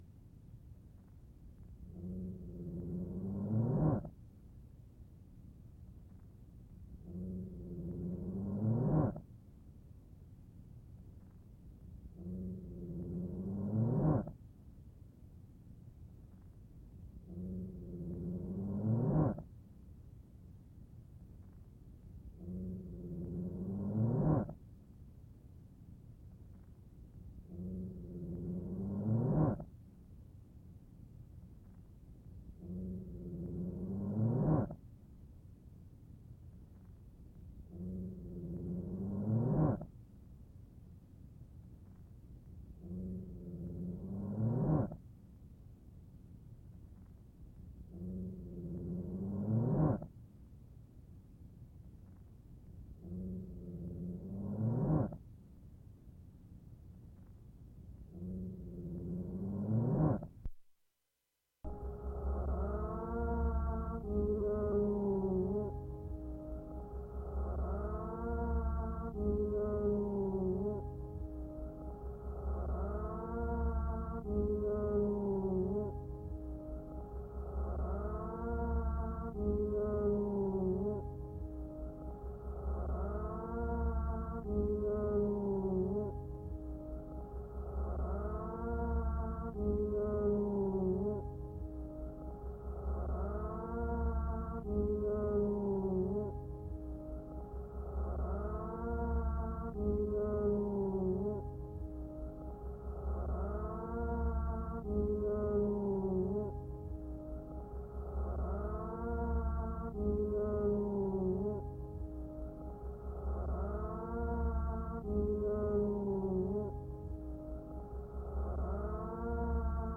nonsense_ii_loop.mp3